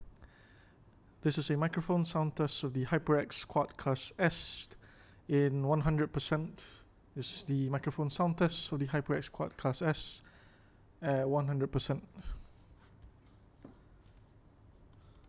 Microphone Sound Test
To give you a clear idea of how the HyperX QuadCast S sounds, I recorded a few samples talking directly to the microphone at 100%, 75%, and 50% sensitivity levels (on PC) so you will know what sensitivity you should set for what you want do with it. The distance between my face and the microphone is about 18-20cm apart.
Personally at 100%, it does sound a little bit soft but that can be alleviated with by using the gain dial on the microphone or bringing the microphone a little bit closer to my face. As it is, I do like how clear my voice comes out with no background noise. For context, I had an air conditioner running at full speed right above my head.